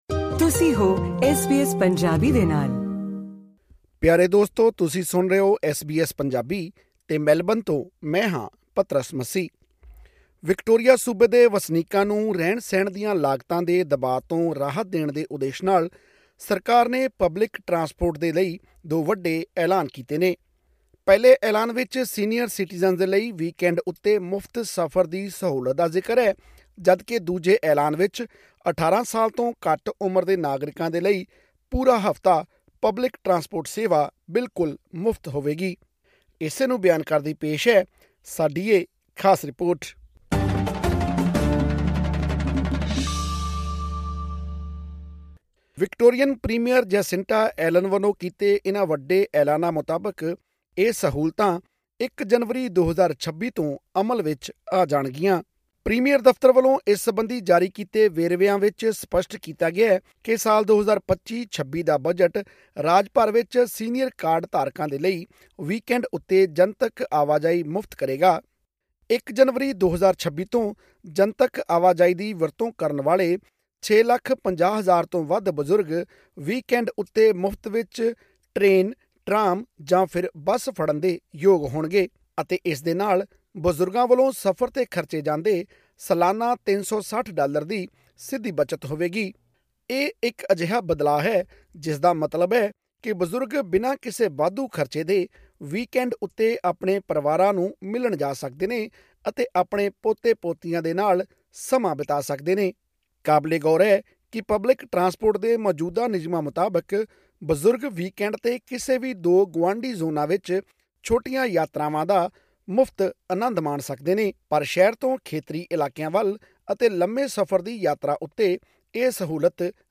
ਹੋਰ ਵੇਰਵੇ ਲਈ ਸੁਣੋ ਇਹ ਖਾਸ ਰਿਪੋਰਟ....